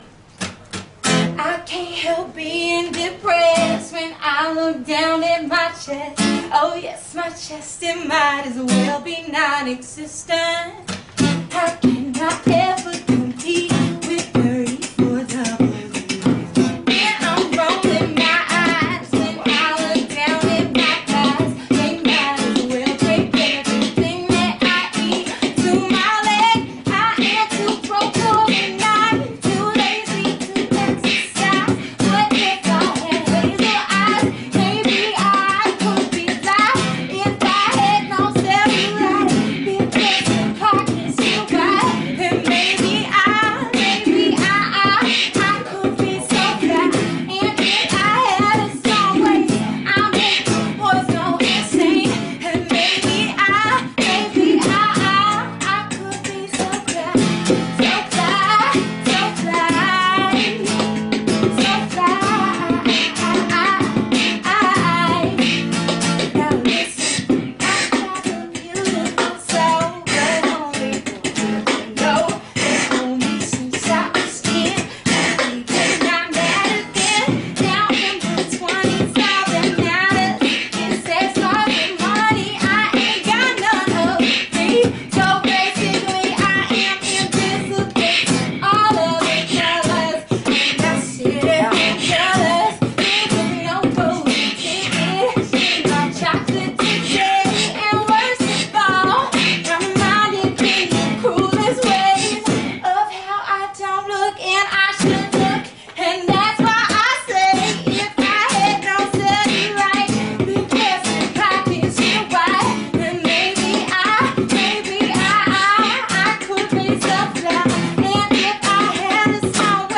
stripped-down performance
with just a beatboxer at her side
guitar
rhythmically playful
(live)